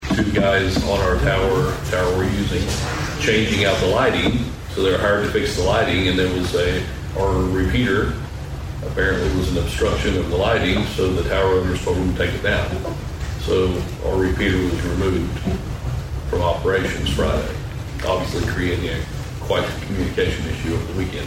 During the citizen's input portion of Tuesday's Osage County Commissioners meeting, Sheriff Bart Perrier informed the Board of some radio connectivity issues they had faced over the weekend.